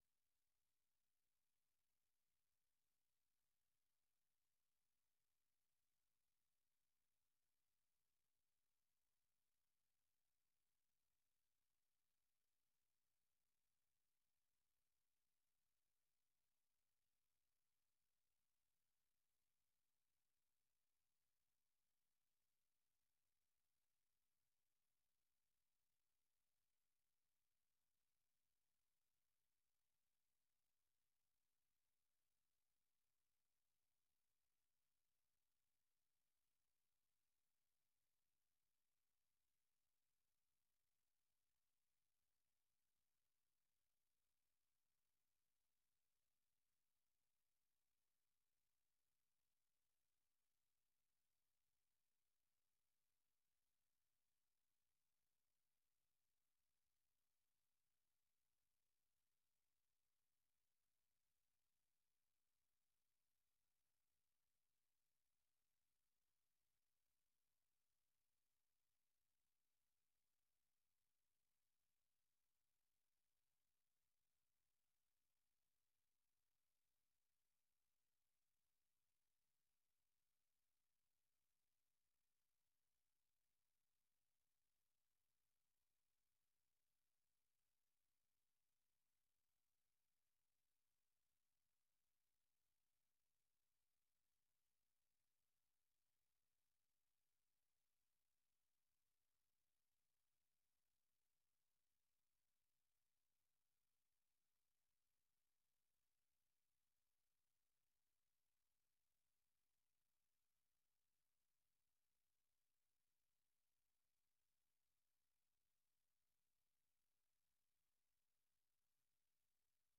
نخستین برنامه خبری صبح
در برنامۀ صبحگاهی خبرهای تازه از افغانستان و جهان و گزارش‌های تحلیلی و مصاحبه ها در پیوند با رویدادهای داغ افغانستان و جهان به شما پیشکش می شود.